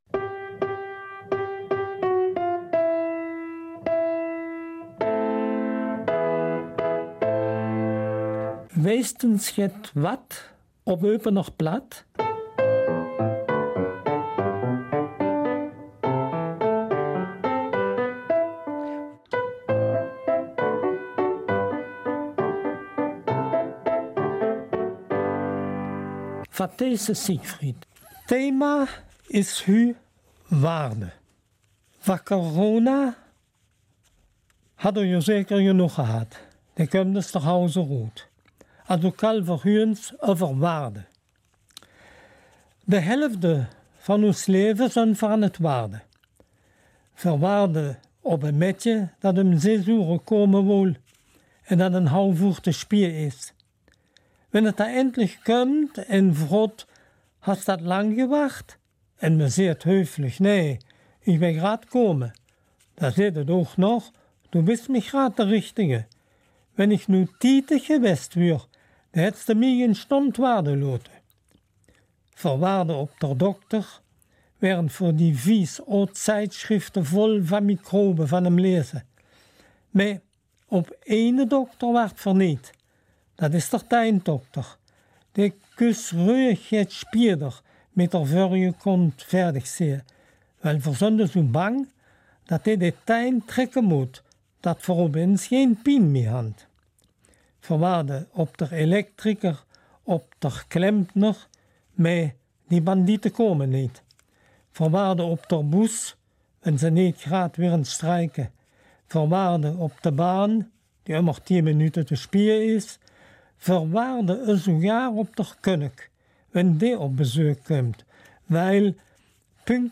Eupener Mundart - 10. Mai